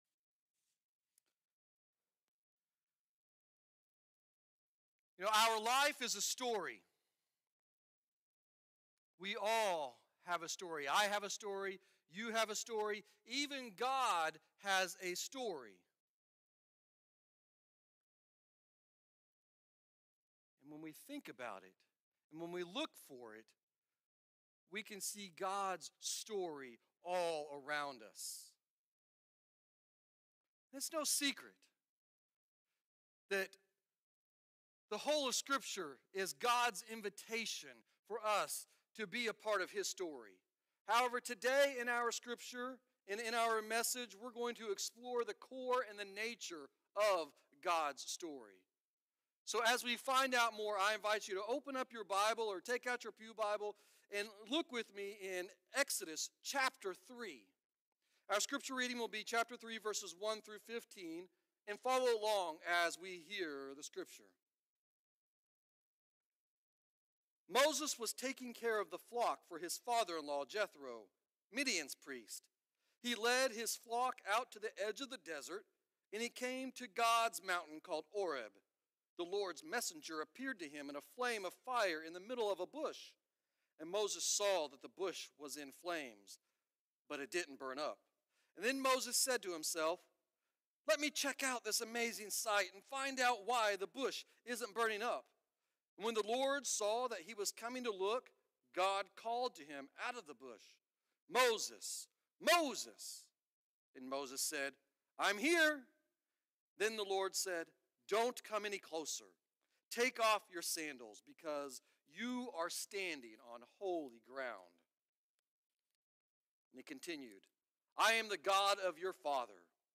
Sermons | First United Methodist Church
Guest Speaker